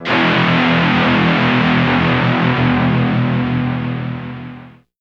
Index of /90_sSampleCDs/Zero-G - Total Drum Bass/Instruments - 2/track43 (Guitars)
02 PowerHausen E.wav